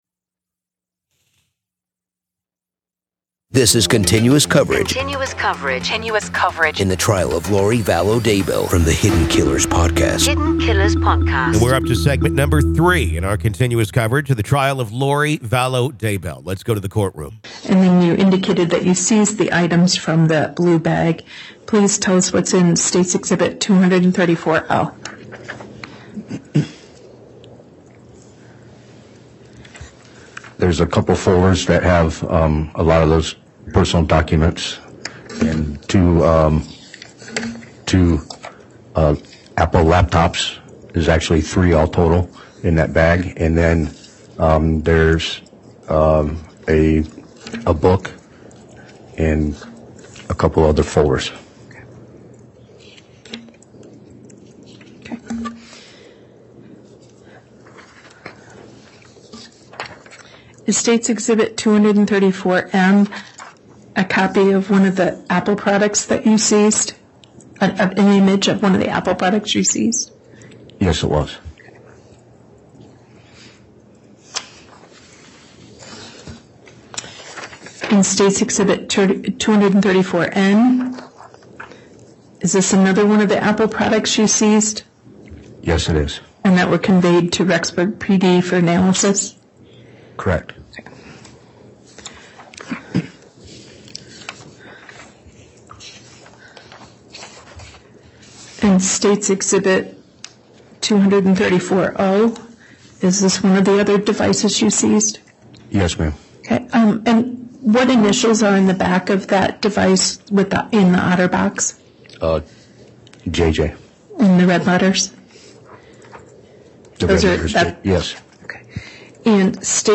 With raw courtroom audio, and interviews from experts and insiders, we analyze the evidence and explore the strange religious beliefs that may have played a role in this tragic case.